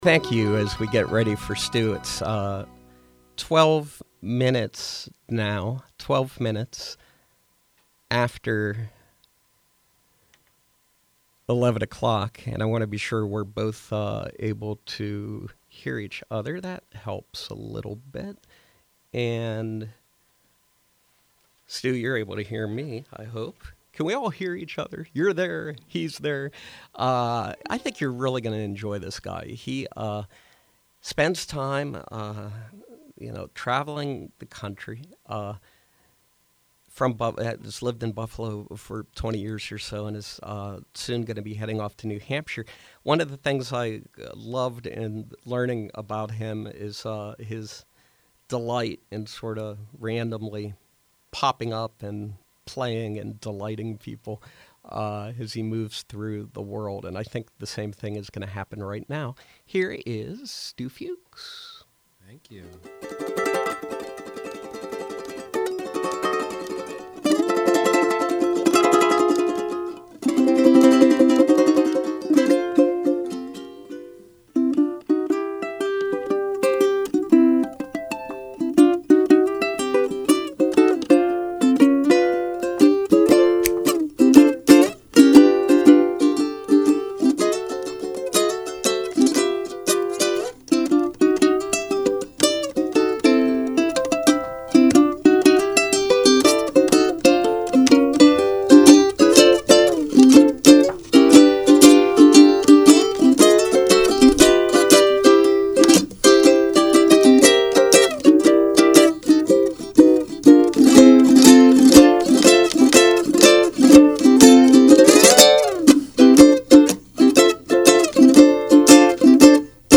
Aboriginal didgeridoo
Brazilian Jazz, Rockabilly and original songs.